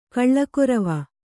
♪ kaḷḷakorava